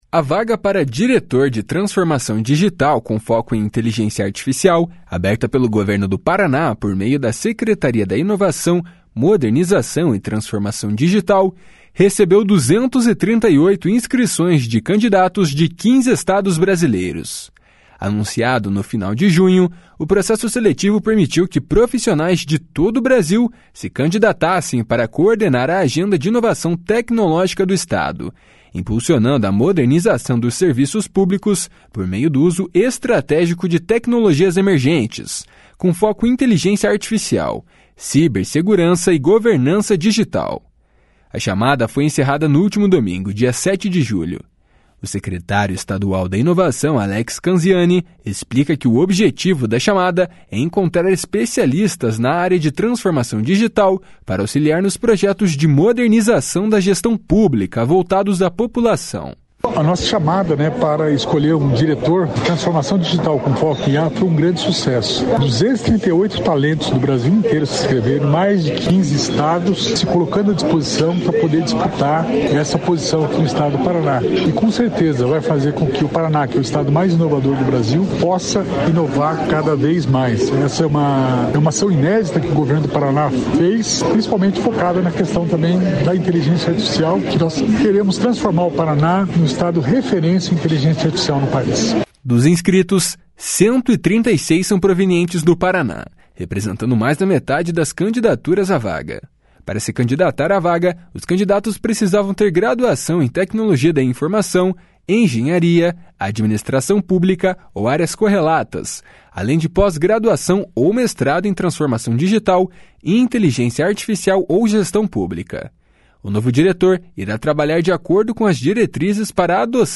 // SONORA ALEX CANZIANI //